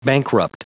Prononciation du mot bankrupt en anglais (fichier audio)
Prononciation du mot : bankrupt